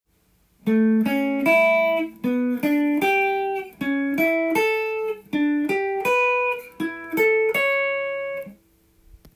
ギターソロでかなり使える【トライアド】
A7を例にして解説です。